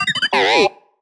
sci-fi_driod_robot_emote_neg_06.wav